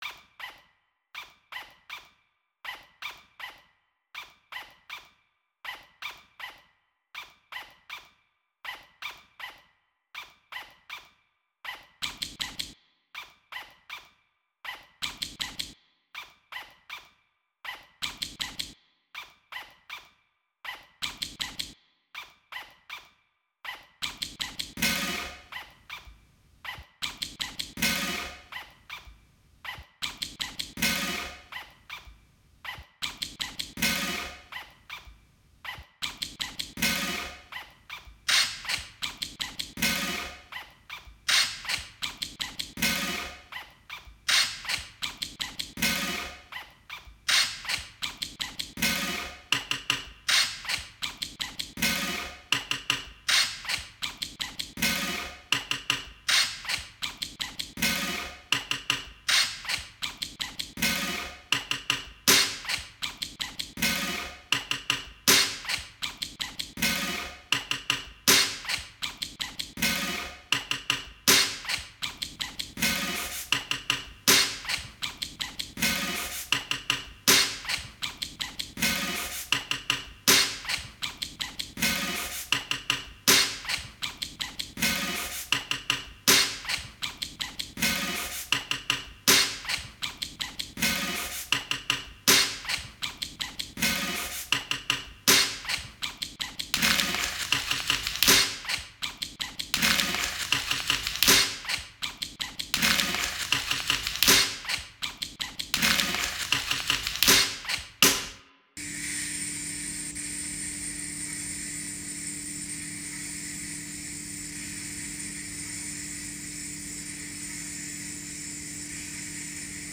novelty percussion
Percussion ensemble